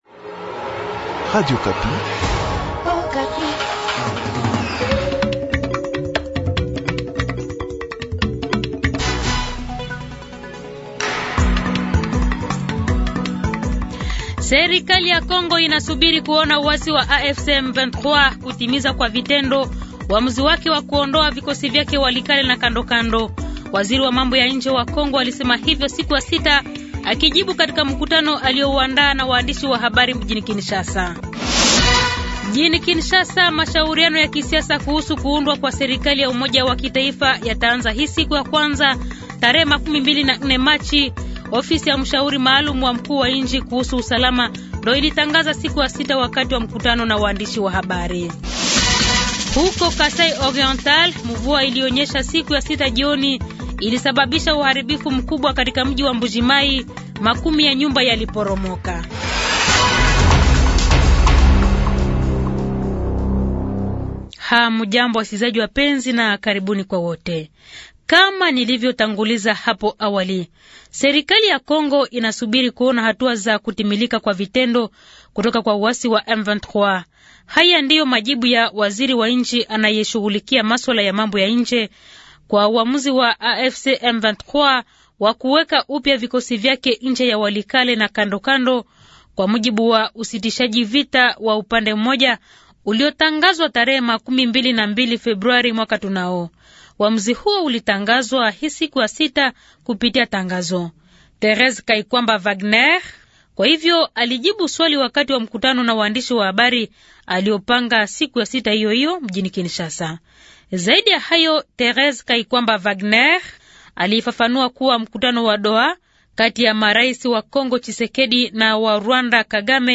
Jounal Matin